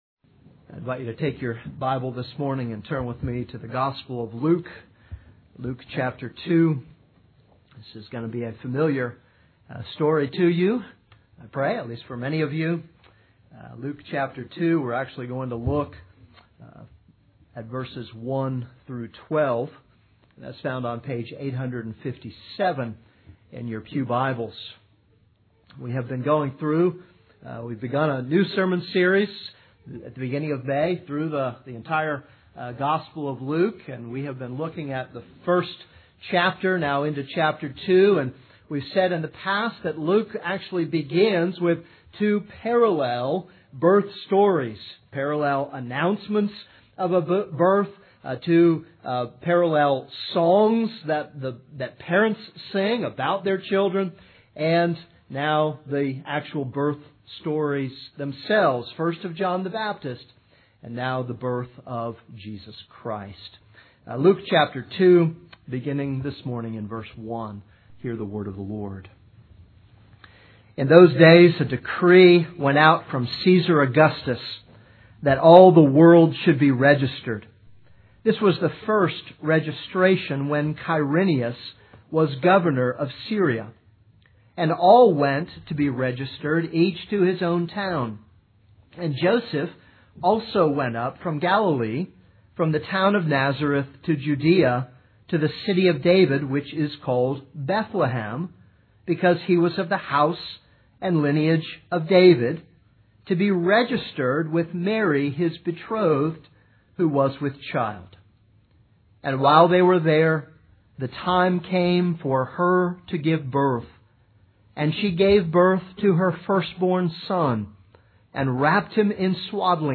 This is a sermon on Luke 2:1-12.